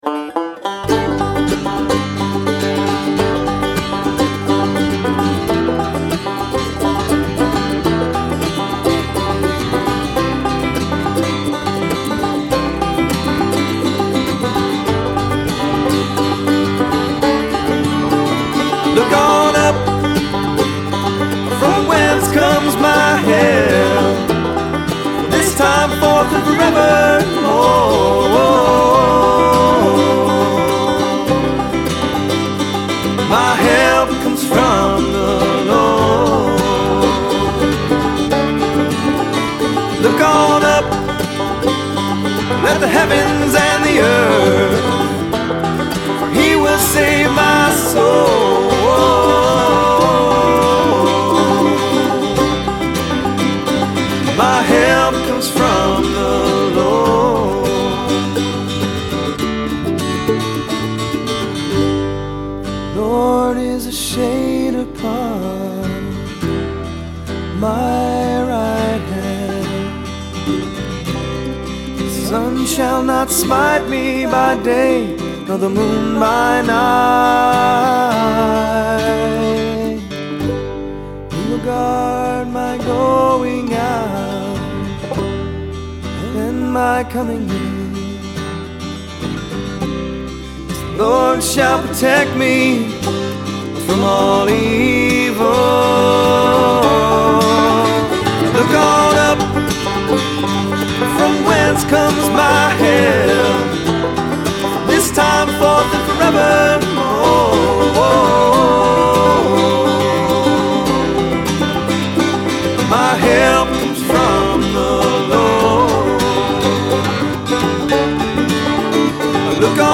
e produzidas em estilo folk e country.